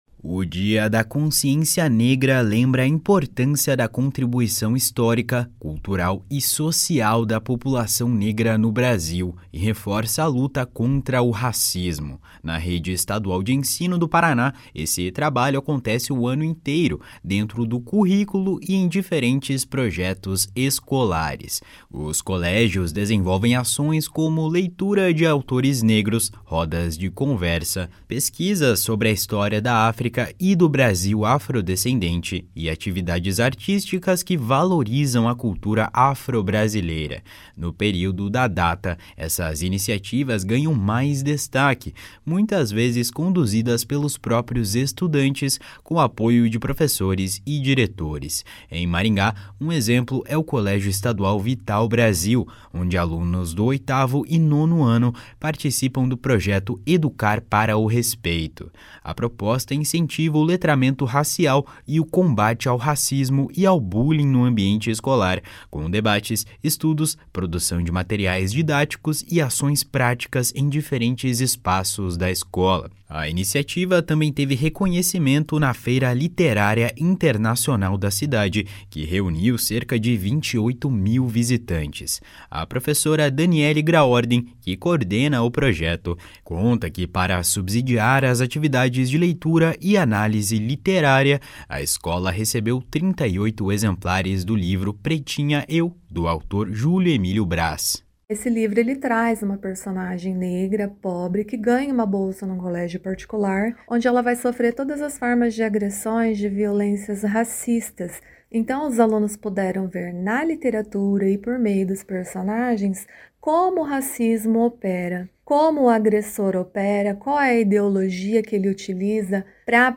A professora destaca a importância da iniciativa.